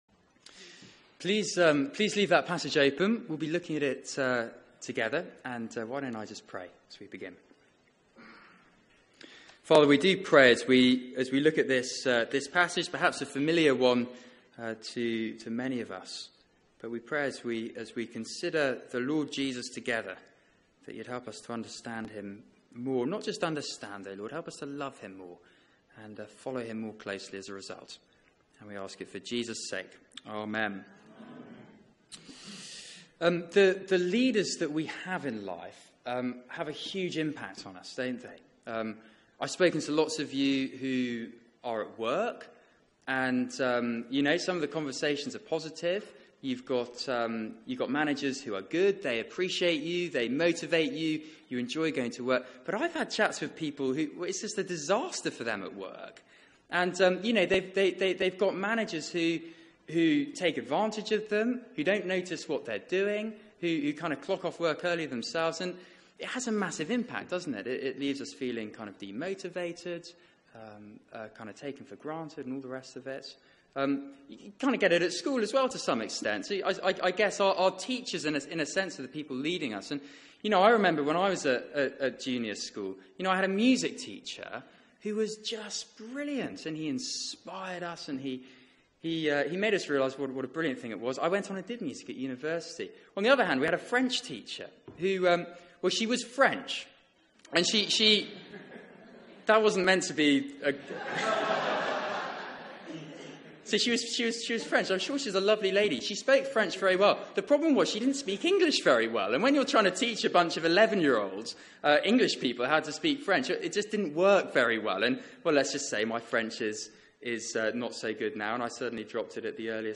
Media for 6:30pm Service on Sun 30th Apr 2017 18:30 Speaker
The King arrives Sermon